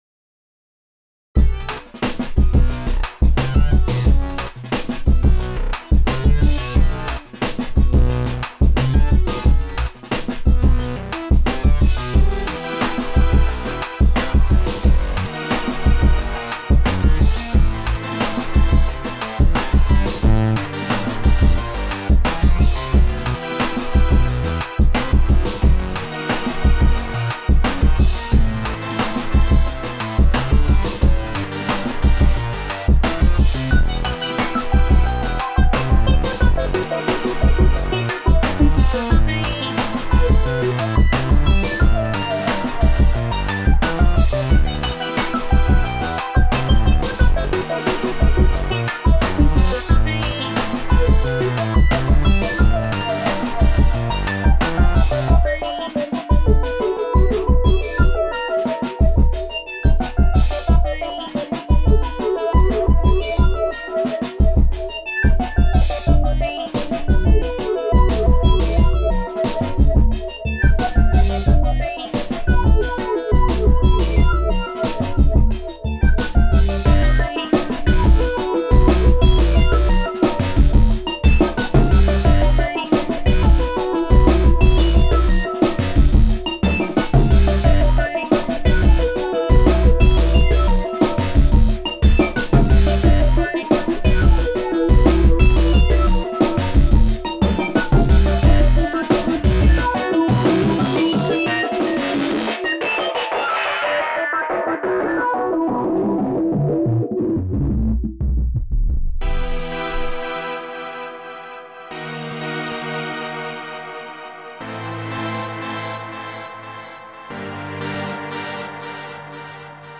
These "songs" are just the results of me playing with Renoise. I usually drop some sounds to the timeline and listen to them looped.